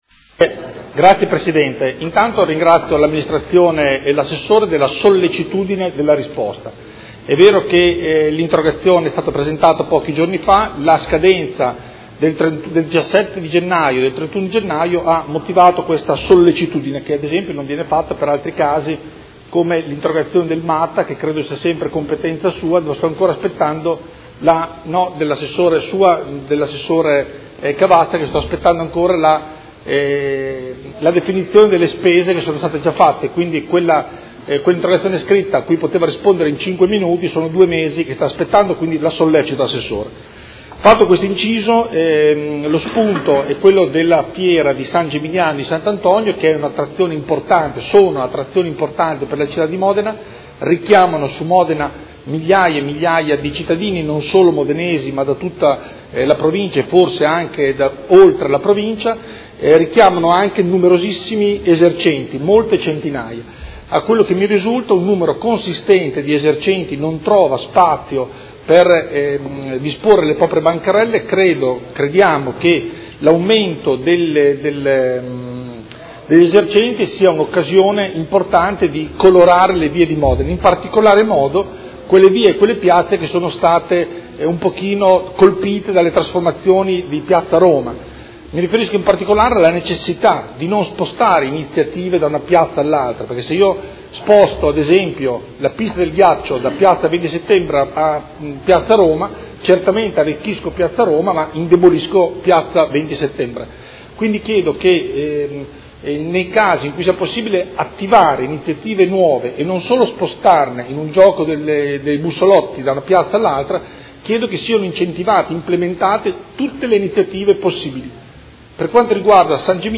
Seduta del 28 gennaio. Interrogazione del Consigliere Galli (F.I.) avente per oggetto: Perché non allargare a Via Farini/Piazza Roma le bancherelle per S. Geminiano?